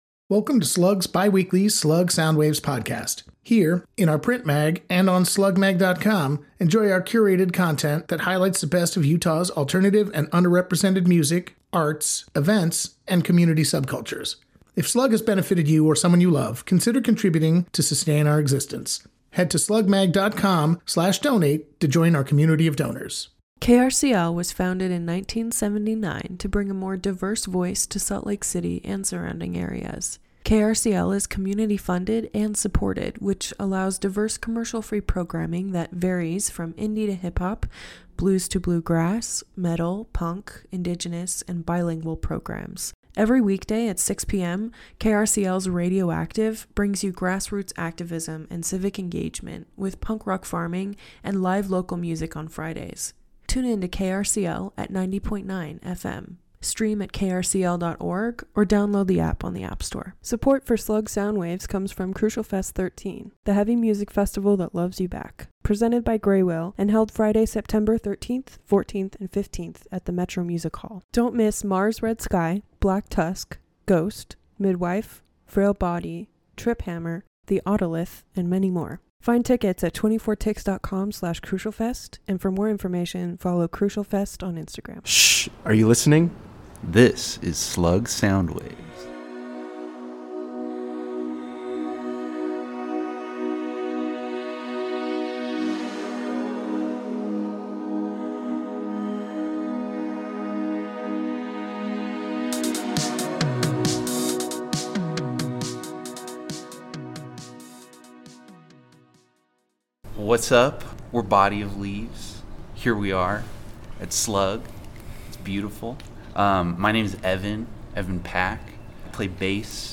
Body of Leaves is has cemented itself as one of SLC’s best post-punk groups. In this episode of Soundwaves, the group discusses their origin, sound and new projects.